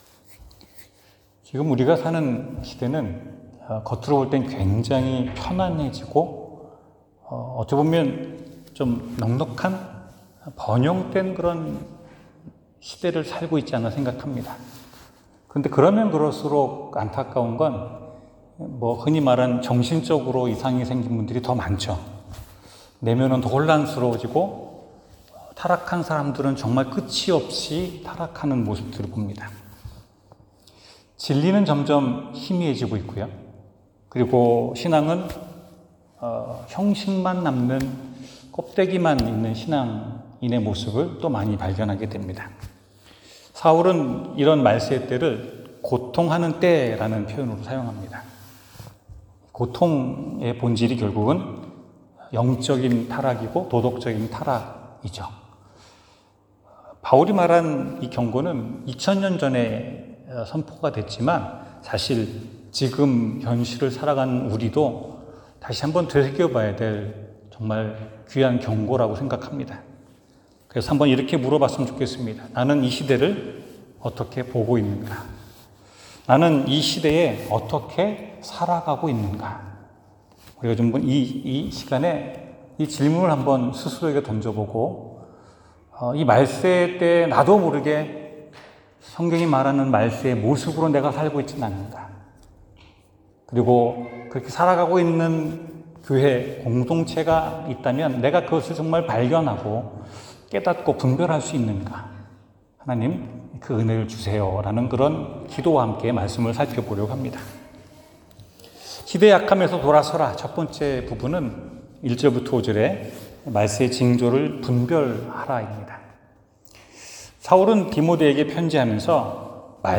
디모데후서 3:1-9 설교